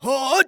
xys蓄力5.wav 0:00.00 0:00.48 xys蓄力5.wav WAV · 41 KB · 單聲道 (1ch) 下载文件 本站所有音效均采用 CC0 授权 ，可免费用于商业与个人项目，无需署名。
人声采集素材